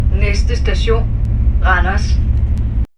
Højttalerudkald - "Næste station.."